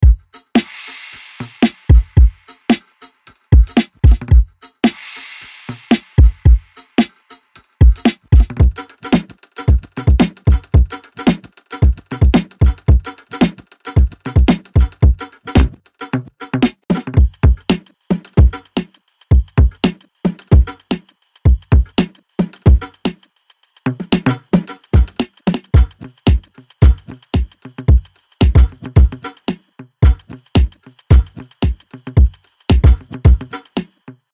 グルーヴィーなローファイ・バウンス、グリッドを超越するファンキー
・にじみ出るローファイ、遊び心、グリッドを超えるグルーブ感あふれるビート
プリセットデモ